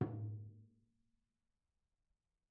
TomH_HitM_v2_rr1_Mid.mp3